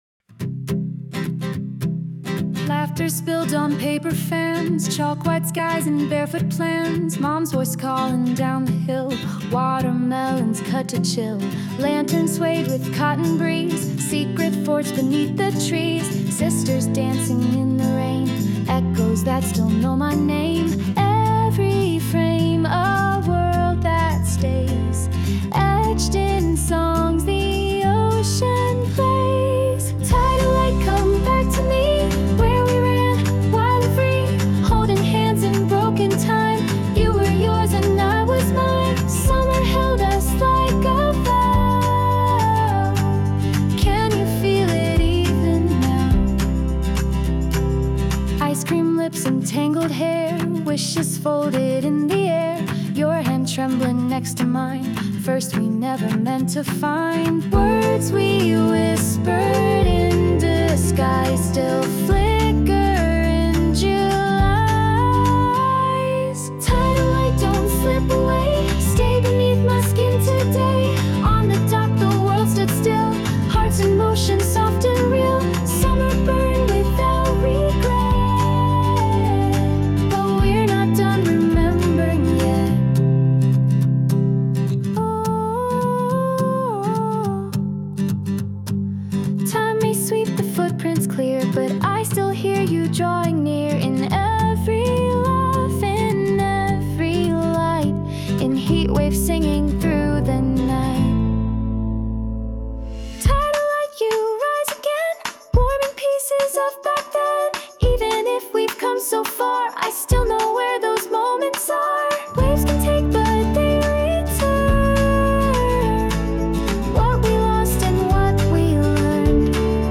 洋楽女性ボーカル著作権フリーBGM ボーカル
女性ボーカル（洋楽・英語）曲です。